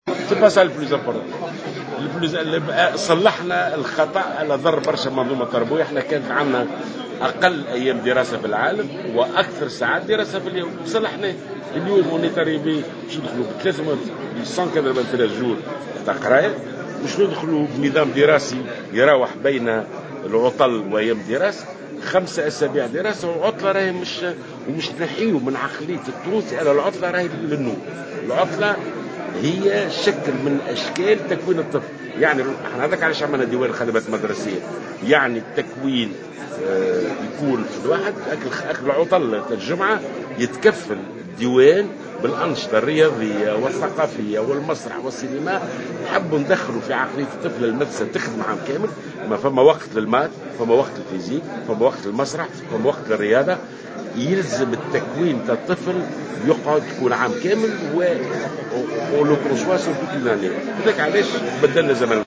وقال لمراسل "الجوهرة اف أم" بالمنستير اليوم الأربعاء إنه سيتم تطبيق نظام 193 يوم دراسية بدءا من السنة الدراسية المقبلة كما سيراوح هذا النظام بين العطل بعد 5 أسابيع دراسة.